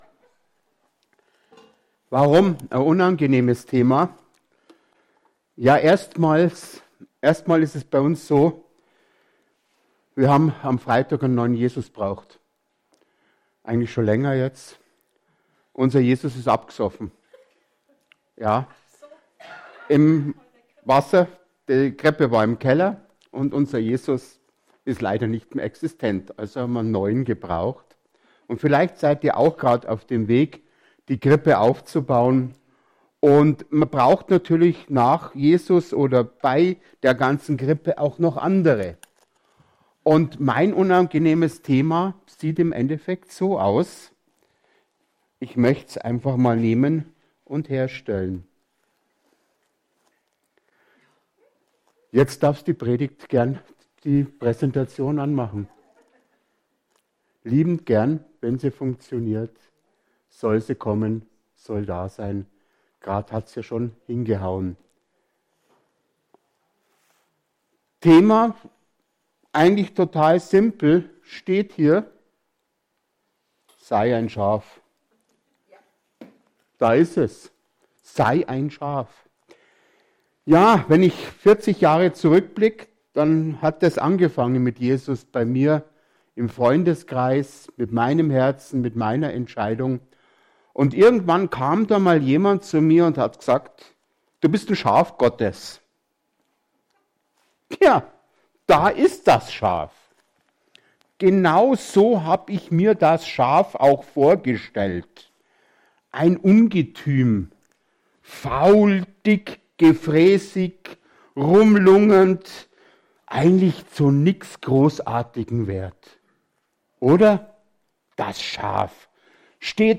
Predigt vom 15.12.2024